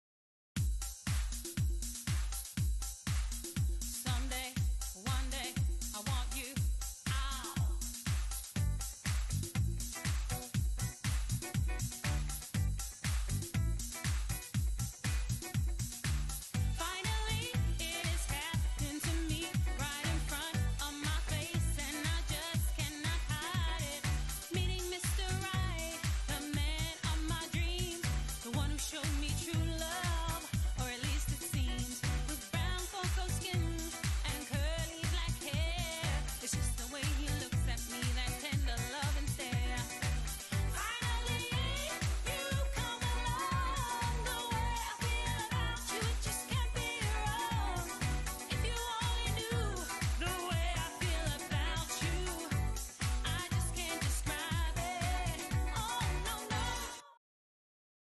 Dance Band Tracks